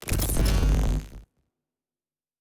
pgs/Assets/Audio/Sci-Fi Sounds/Electric/Device 8 Start.wav at master